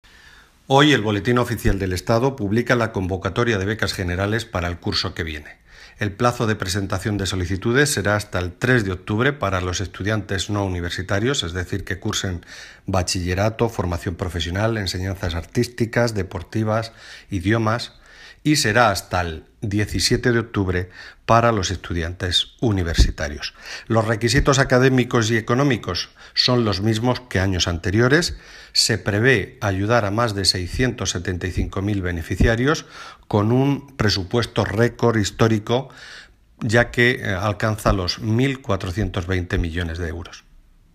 Palabras del secretario de Estado de Educación, Formación Profesional y Universidades, Marcial Marín Audio